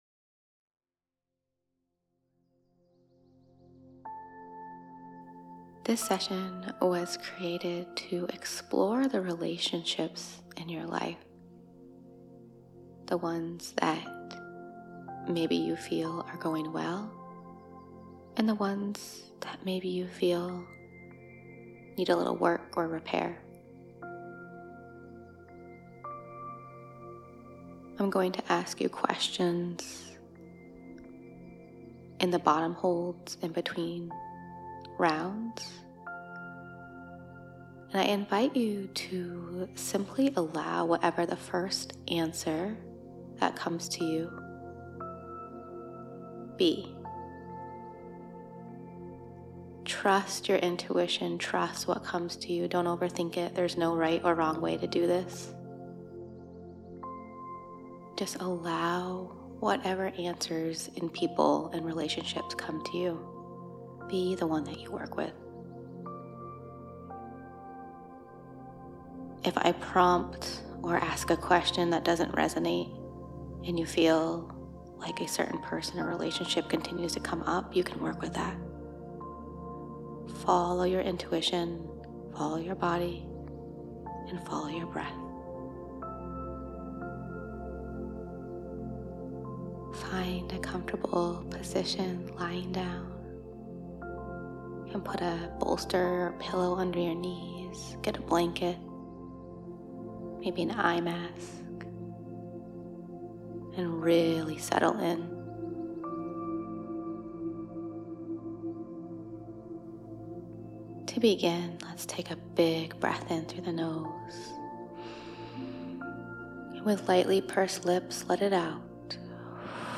Heart-centered professionally guided Breathwork sessions to calm, clear, and energize.
Easy to follow breaths with intuitively chosen music.